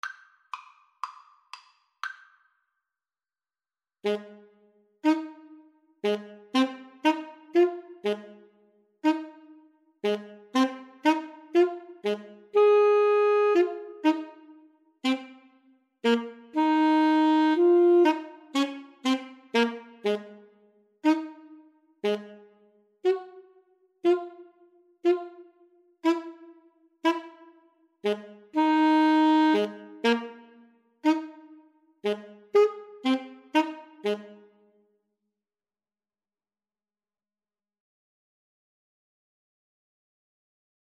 Vivo
Arrangement for Alto Saxophone Duet
Db major (Sounding Pitch) Ab major (French Horn in F) (View more Db major Music for Alto Saxophone Duet )